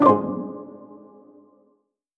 button-back-select.wav